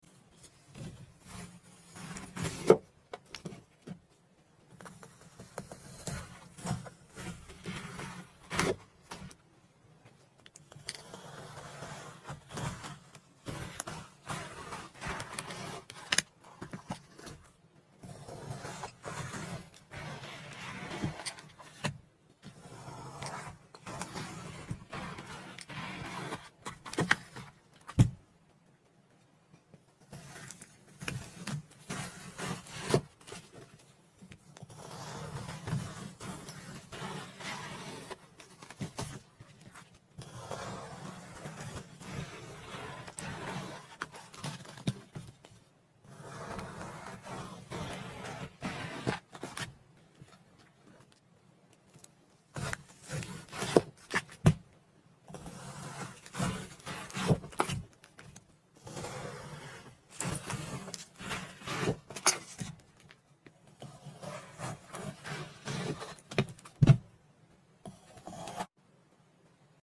Relájate Con Sonidos Asmr Ai Sound Effects Free Download